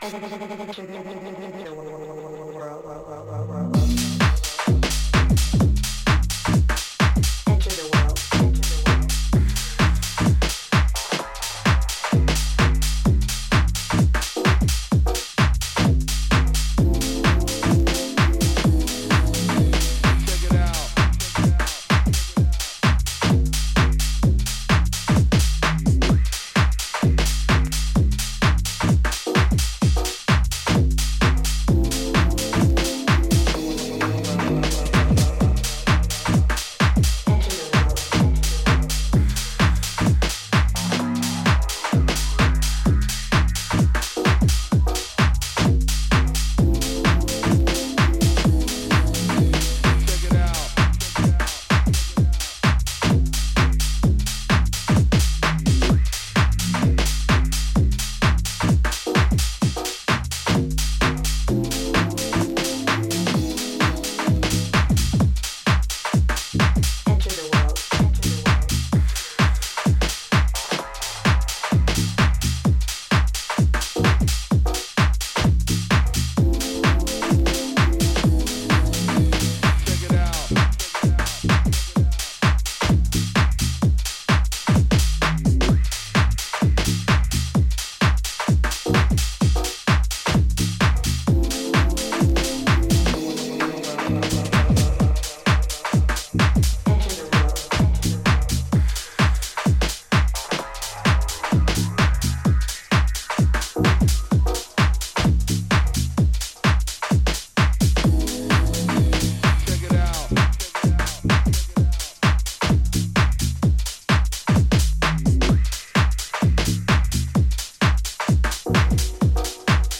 いずれの楽曲も当レーベルらしいカラーの、ソリッドかつ極めて圧の強いミニマル・ハウス！